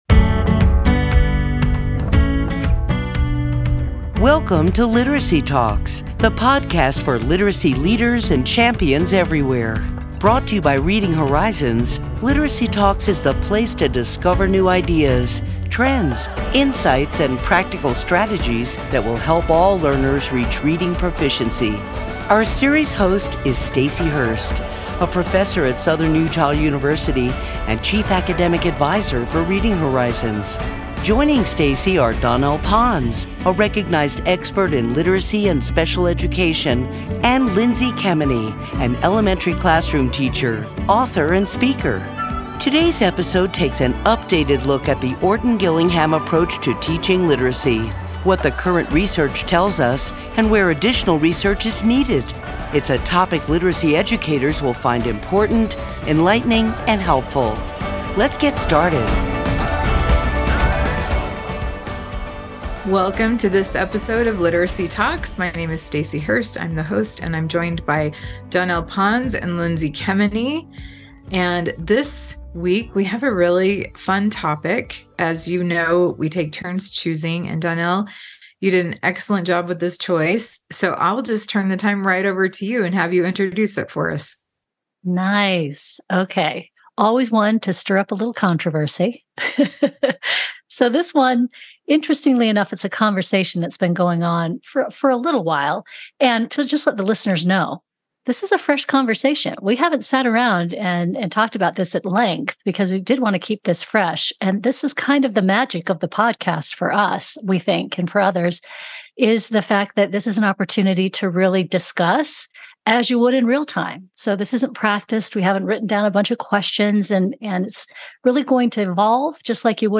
In this episode of Literacy Talks, our hosts talk in-depth about the research supporting key components of the “OG” approach and explore where additional research is needed. The spark for this conversation is an important article published in October of 2023 in The Reading League Journal.